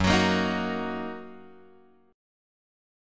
F#6 chord {2 x 4 3 4 2} chord